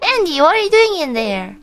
Worms speechbanks
Orders.wav